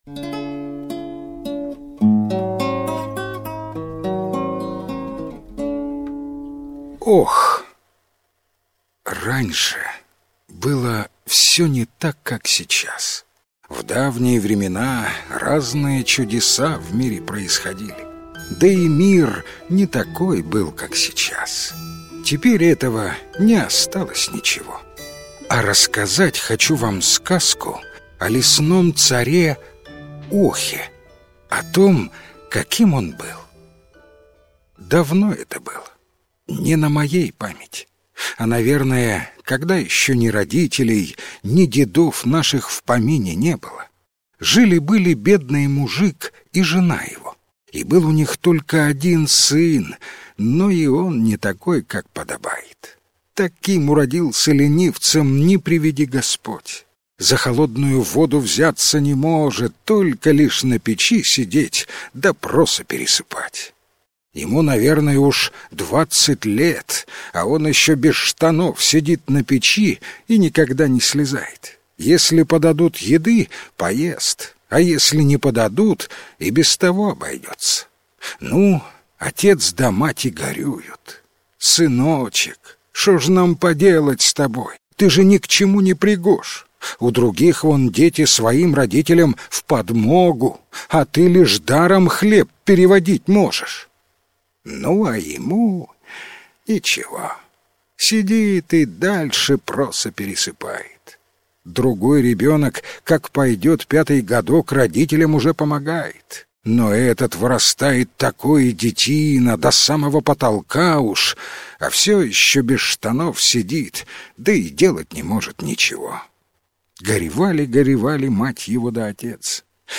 Ох - украинская аудиосказка - слушать онлайн